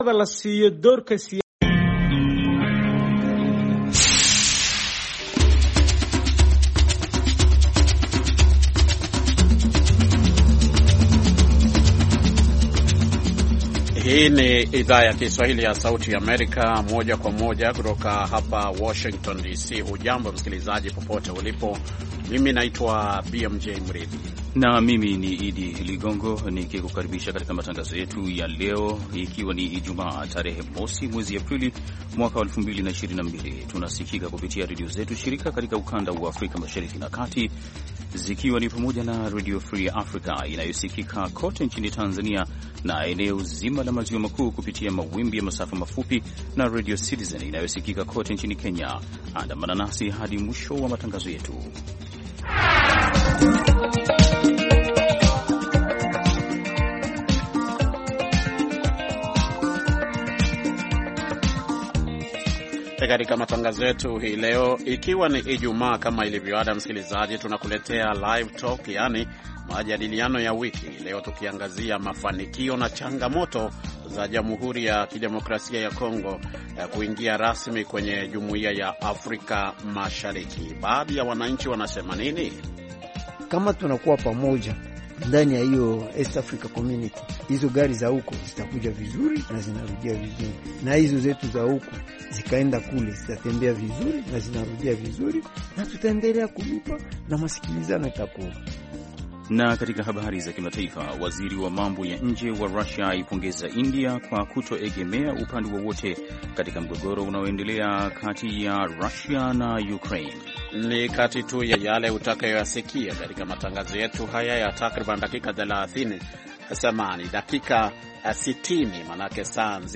Majadiliano ya wiki, Livetalk, kuhusu DRC kujiunga na Jumuiya ya Afrika Mashariki
Wageni wetu, wakiwa ni pamoja na Katibu Mkuu wa Jumuiya ya Afrika Mashariki Dr Peter Mathuki, wanaangazia mafanikio na changamoto za hatua ya Jamhuri ya Kidemokrasia ya Kongo kujiunga rasmi na Jumuiya ya Afrika Mashariki.